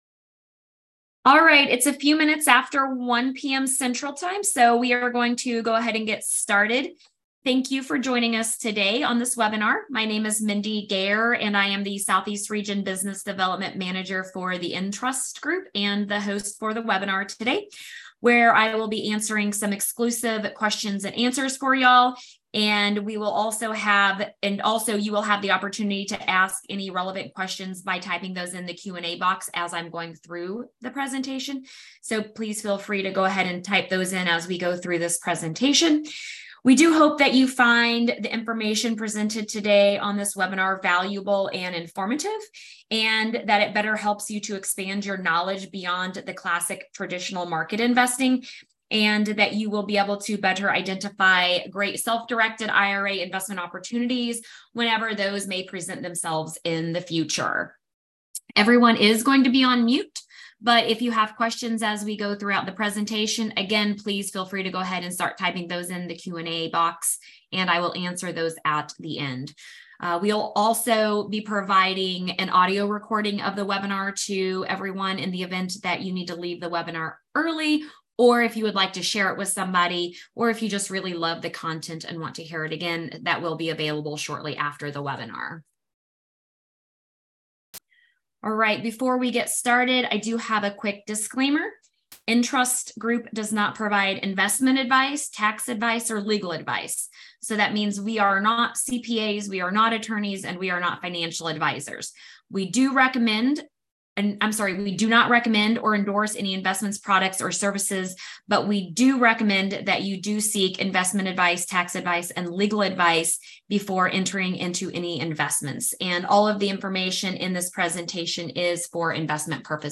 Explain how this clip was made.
In this invite-only webinar, we answered your SDIRA questions answered in a relaxed group session.